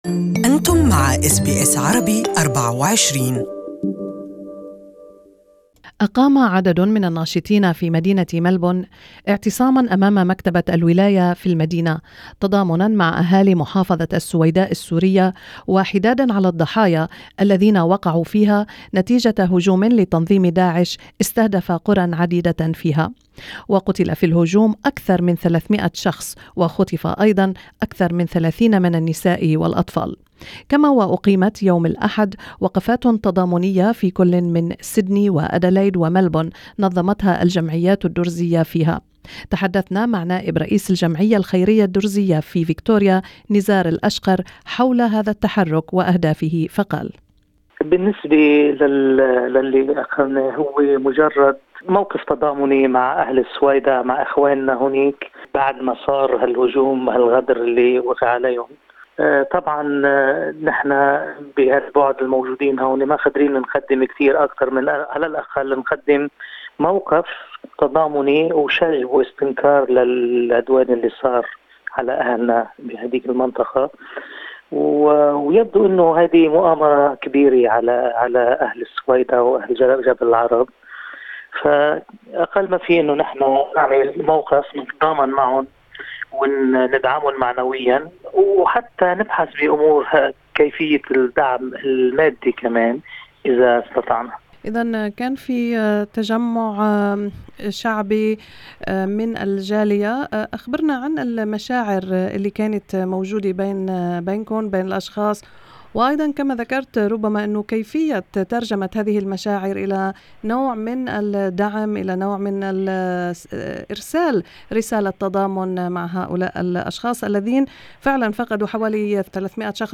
The interview was conducted in Arabic.